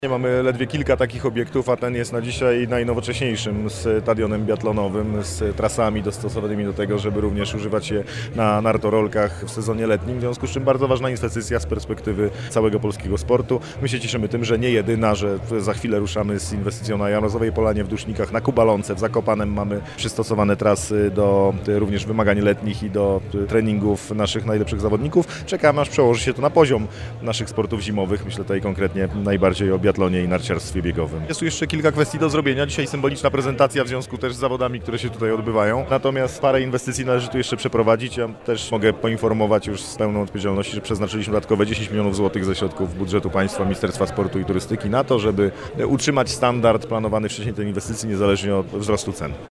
– To najnowocześniejszy obiekt biathlonowy. Ministerstwo sportu i turystyki dołożyło 10 mln złotych, by utrzymać najwyższy standard ośrodka – mówi Kamil Bortniczuk – minister sportu i turystyki.